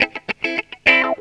GTR 23 AM.wav